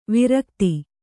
♪ virkti